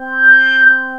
ARP LEAD 2.wav